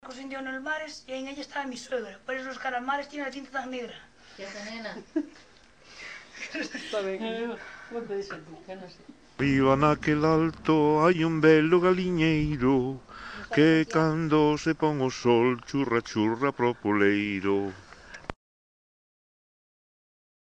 Coplas
Tipo de rexistro: Musical
Soporte orixinal: Casete
Datos musicais Refrán
Instrumentación: Voz
Instrumentos: Voces mixtas